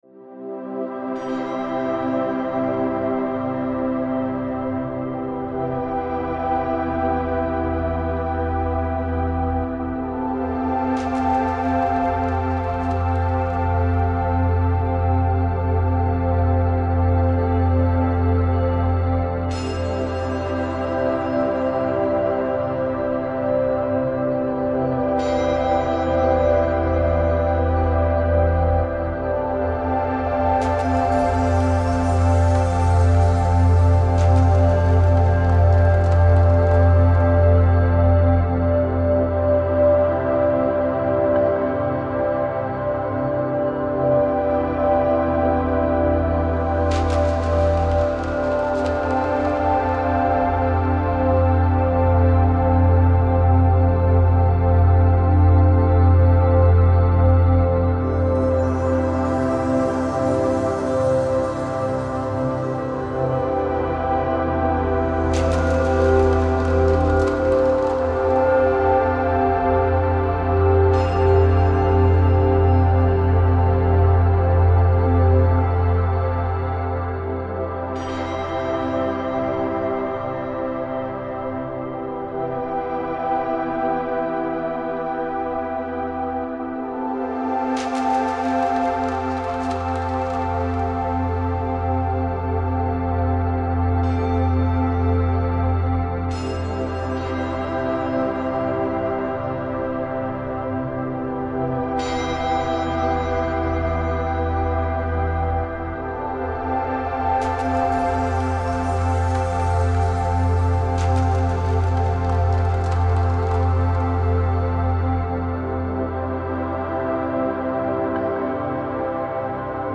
Musik-, Solfeggio-Frequenzen harmonisieren zusätzlich